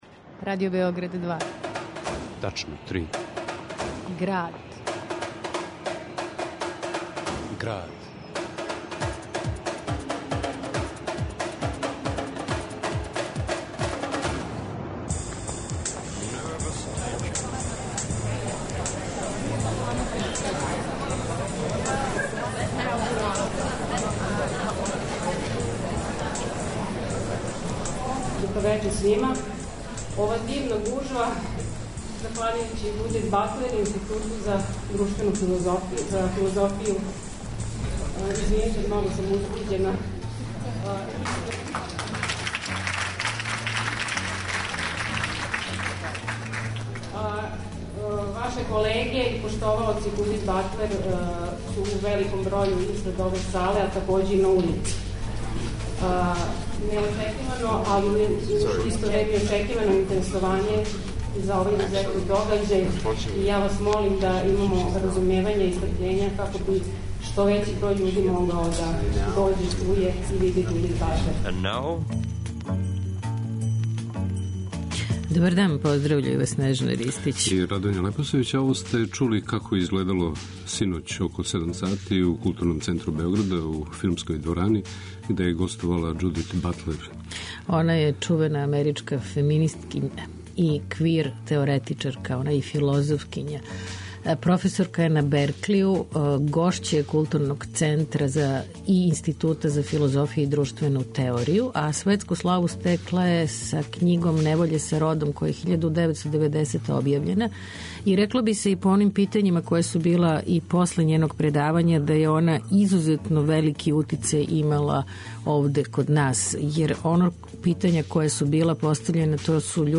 У Граду, говори Џудит Батлер. Чућемо делове њеног београдског предавања, али и аутобиографске исказе из једног документарног филма о њој.